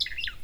warbler.003.wav